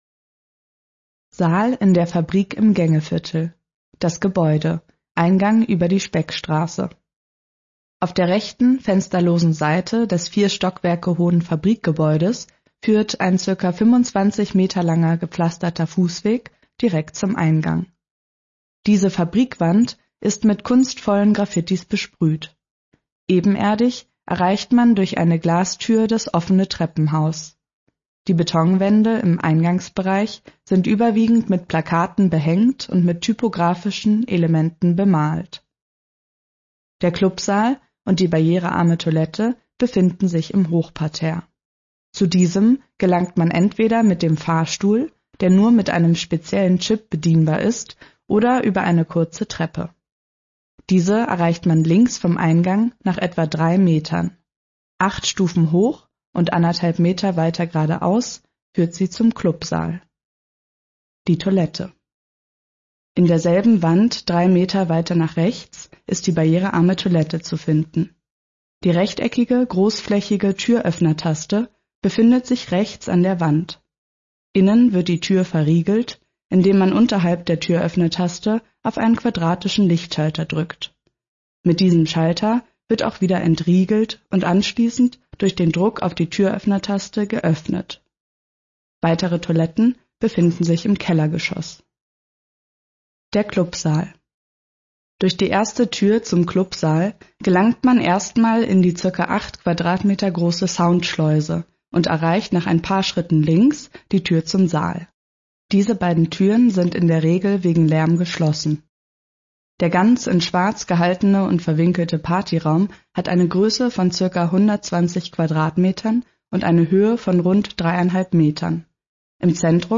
Beschreibung: Saal in der Fabrique (Audiodiskreption)